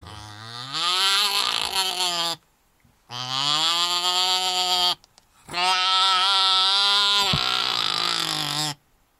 Звук крика болотной нутрии